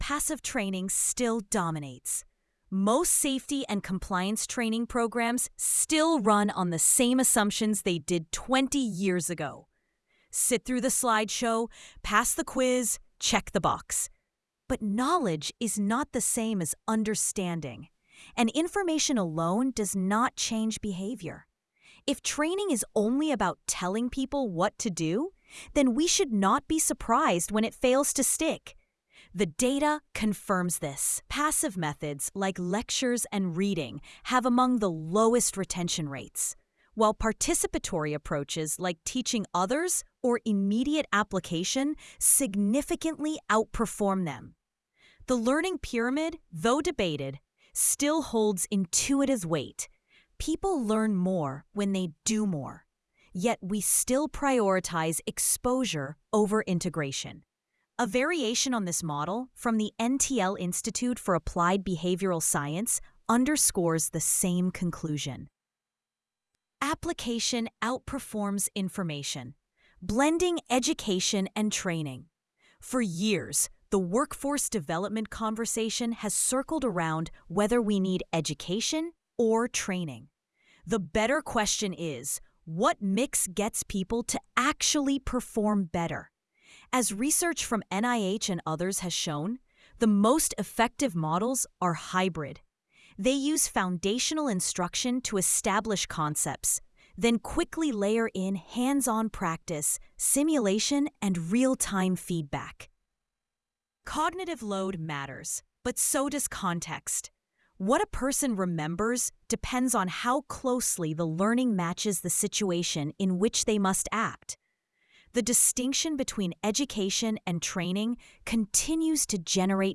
sage_gpt-4o-mini-tts_1x_2025-06-30T06_45_25-853Z.wav